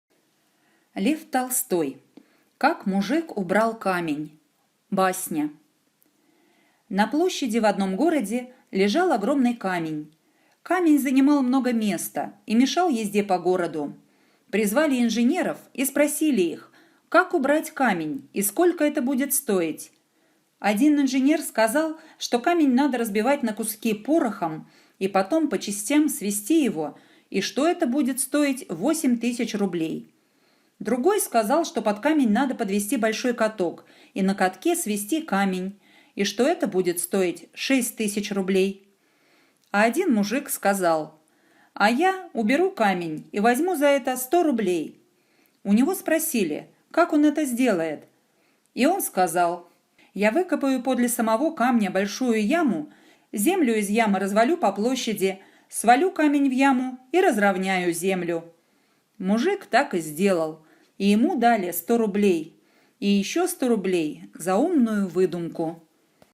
Как мужик убрал камень – аудио басня Толстого
Аудиокнига в разделах